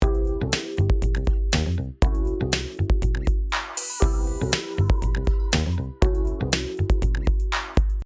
The master bus EQ is meant to be a gentle and wide band EQ which balances overall frequency bands as opposed to individual sounds and instruments.
The loop before EQ-ing.
EQ Before.mp3